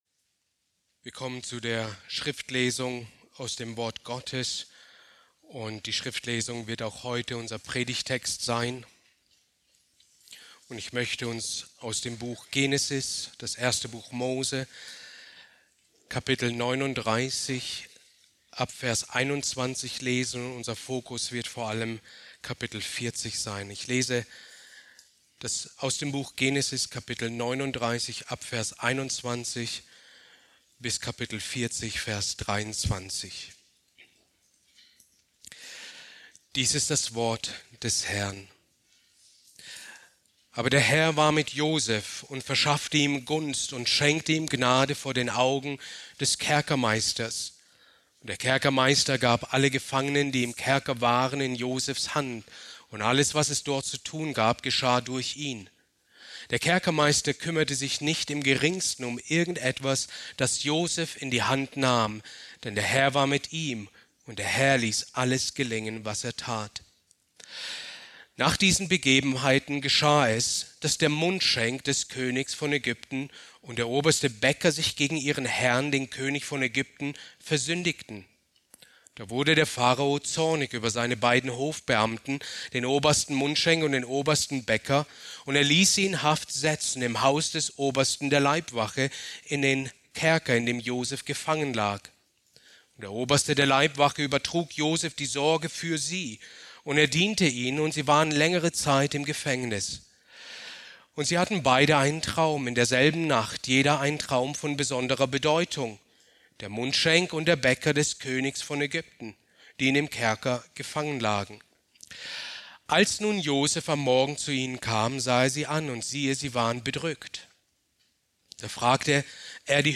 Predigt aus der Serie: "Christologie"